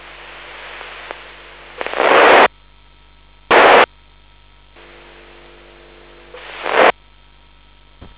Телеметрия на частоте 163.525_Киев